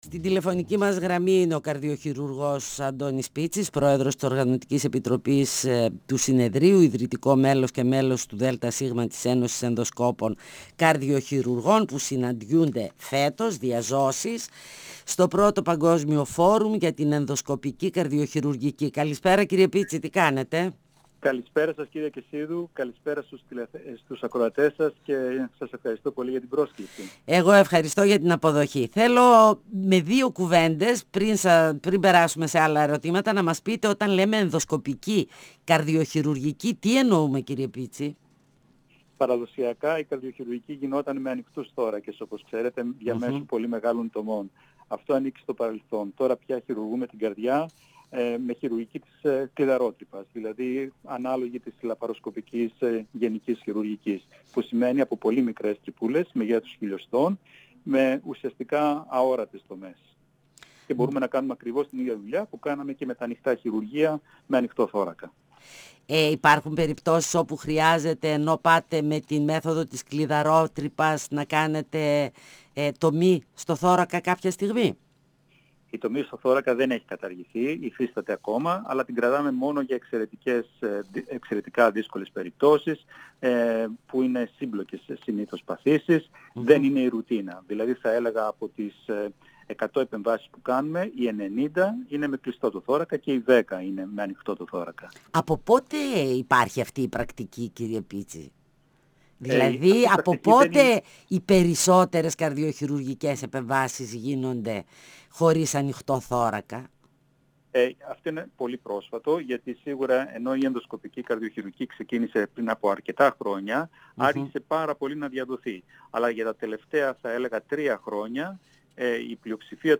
102FM Συνεντεύξεις